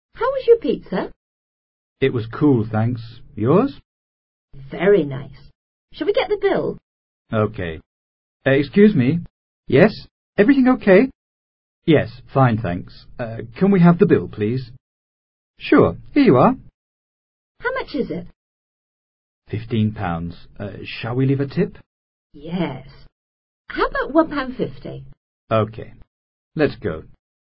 Diálogo que recrea una conversación entre un mesero y dos clientes de un restaurant, los cuales han terminado de comer y se encuentran a punto de retirarse.